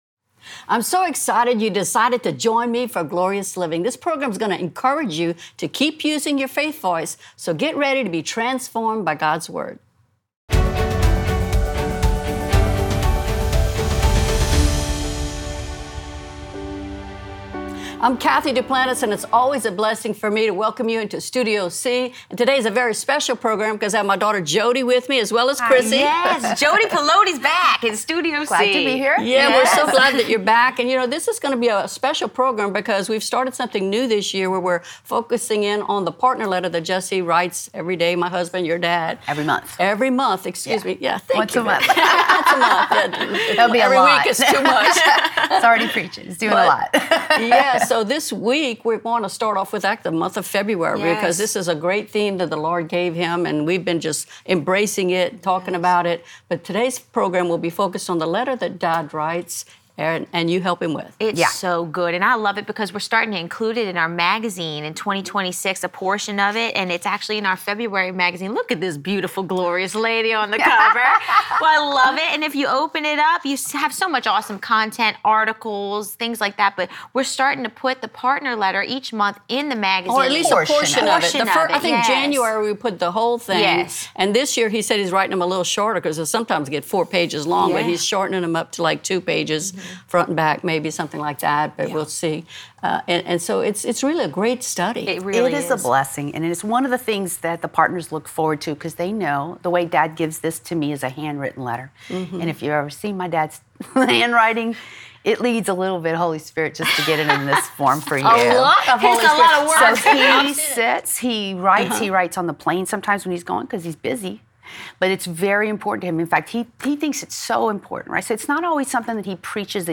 in Studio C